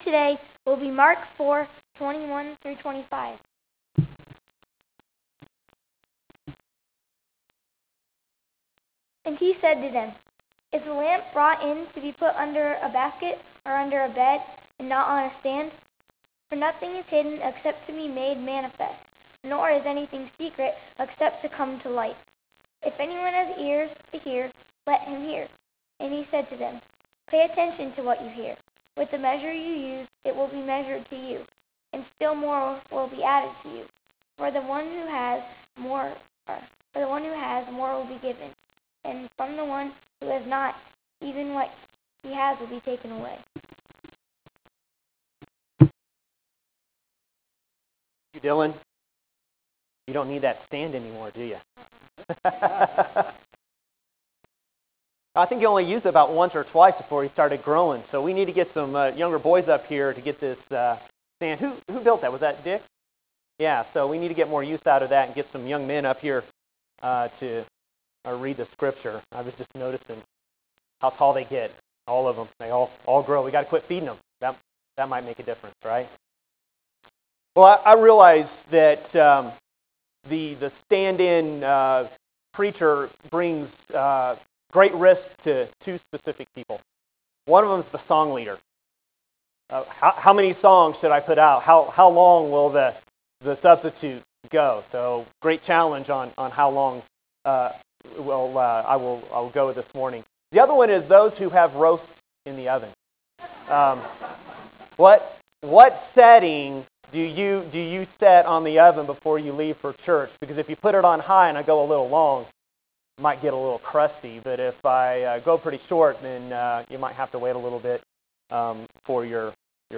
Aug 10 2014 AM sermon